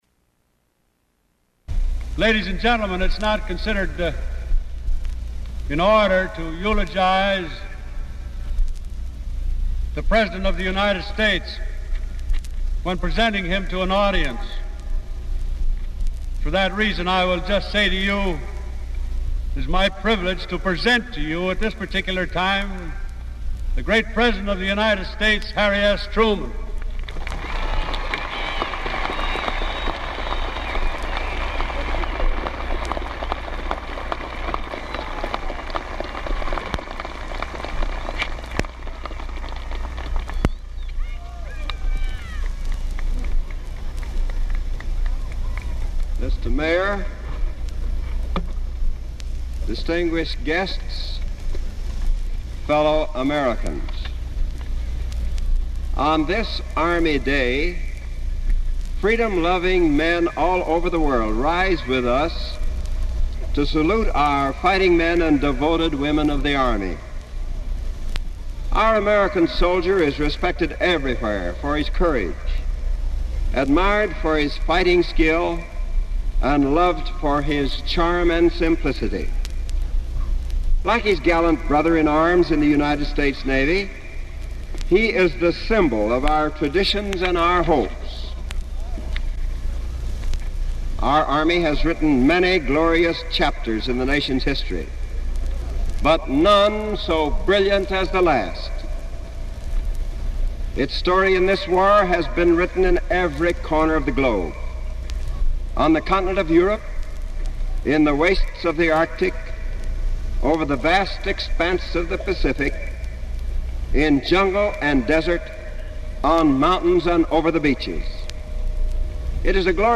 Army Day address in Chicago, Illinois
Subjects Economic assistance, American Reconstruction (1939-1951) Diplomatic relations Europe United States Material Type Sound recordings Language English Extent 00:25:56 Venue Note Broadcast 1946 April 6.